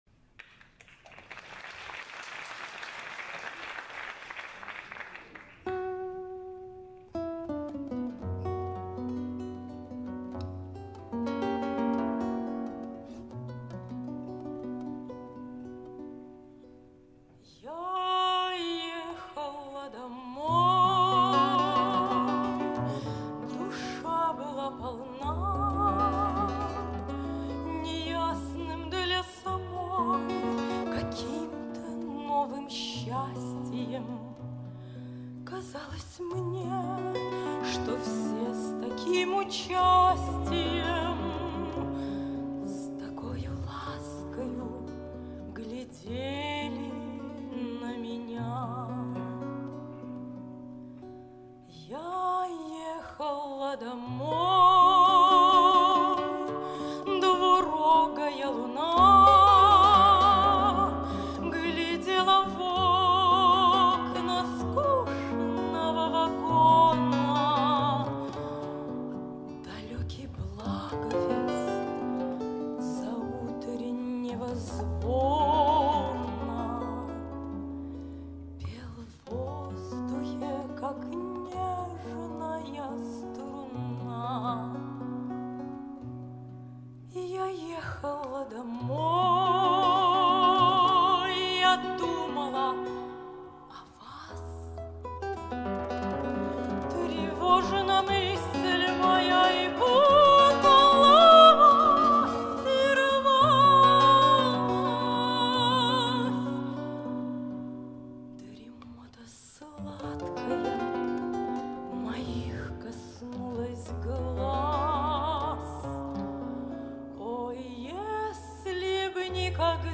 Жанр: Романсы
акустическая гитара
певица с волшебным голосом.
(звук сняла с видео на youtube)
Голос чистый и красивый.